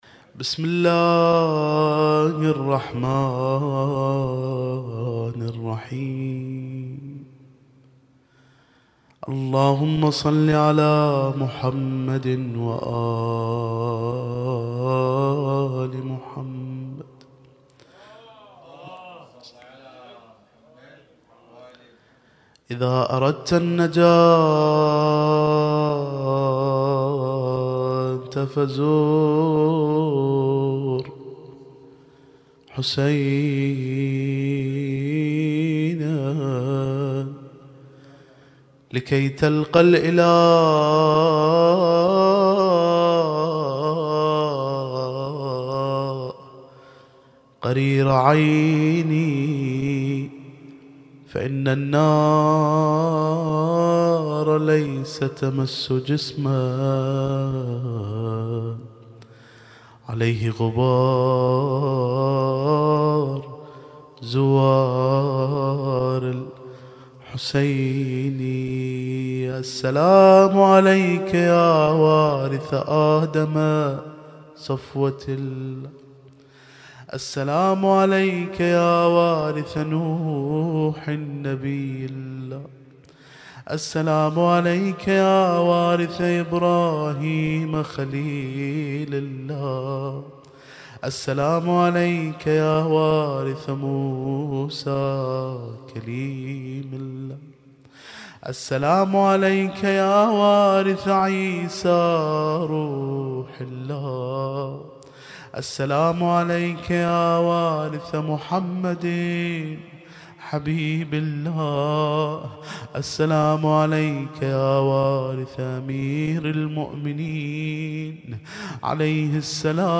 القارئ: - الرادود